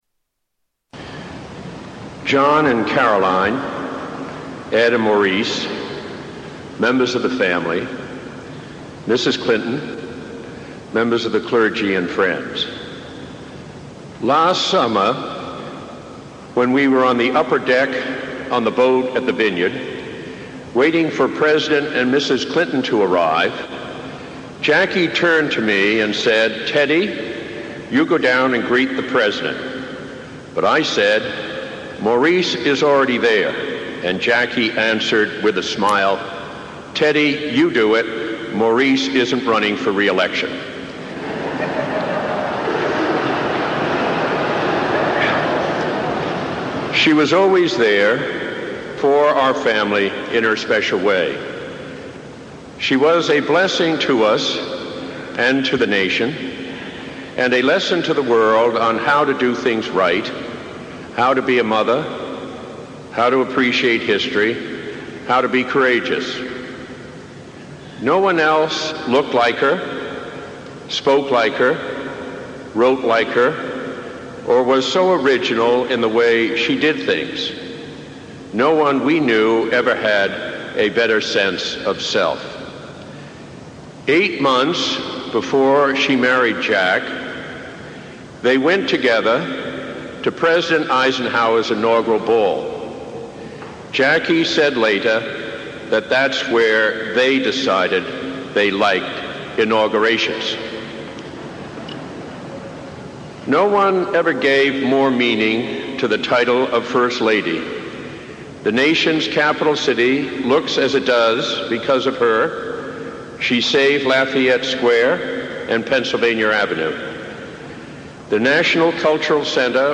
Ted Kennedy's Eulogy for Jackie